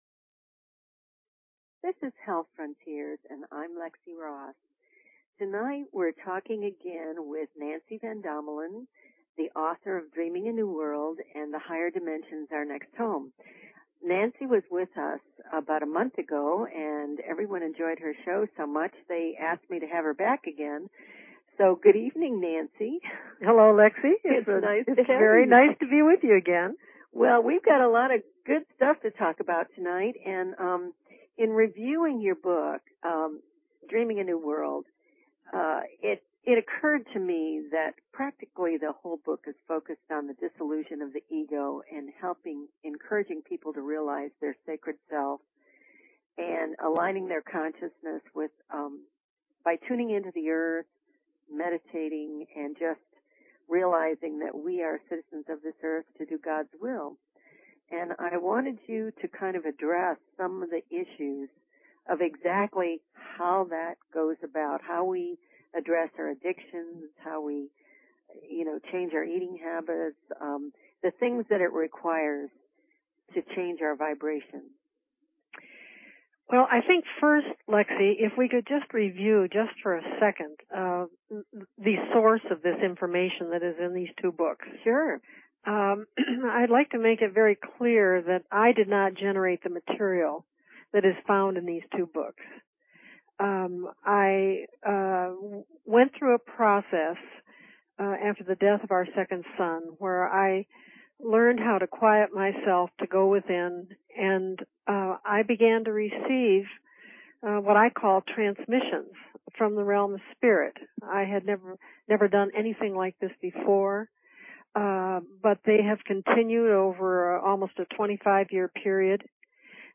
Talk Show Episode, Audio Podcast, Health_Frontiers and Courtesy of BBS Radio on , show guests , about , categorized as